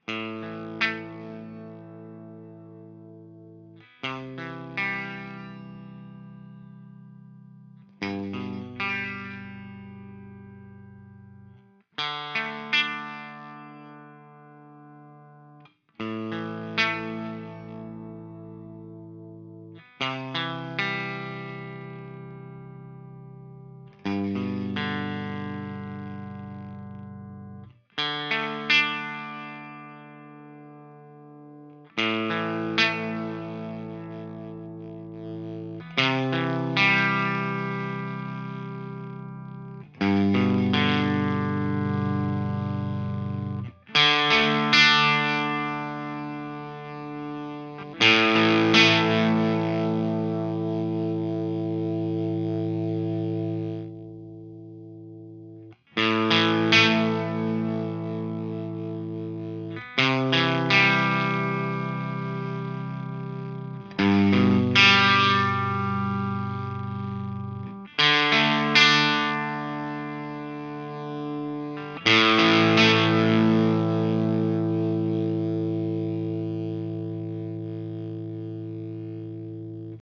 But no active pickups. I am attaching a recording made – guitar straight into unit.
Halfway through I turned the more setting on and that extra distortion tone seems to resolve.